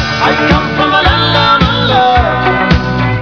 (short sound)